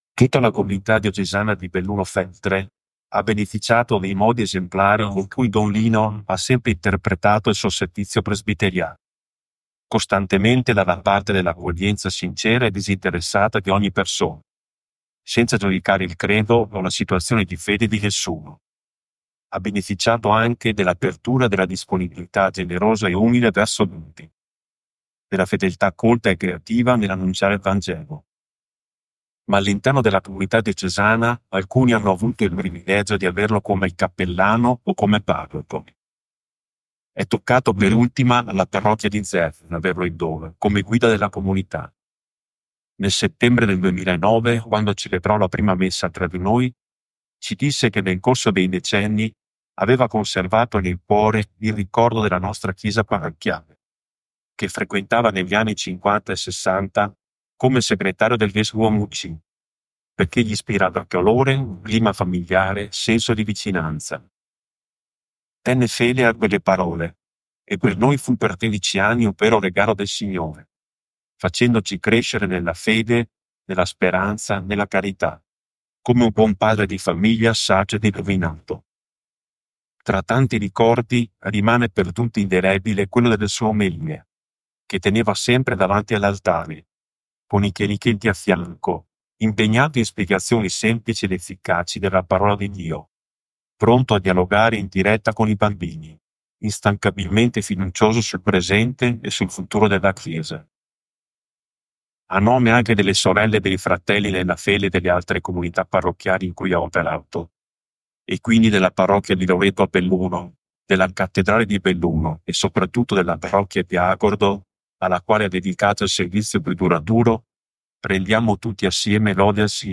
IL RICORDO DELLA COMUNITA’ DI ZERMEN
MEMBRI-DELLA-COMUNITA-DI-ZERMEN.mp3